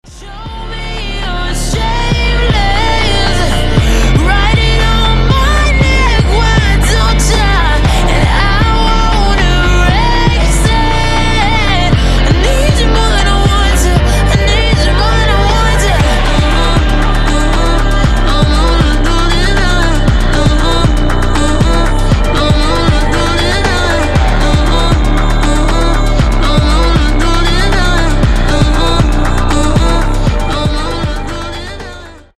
Рингтоны Ремиксы » # Поп Рингтоны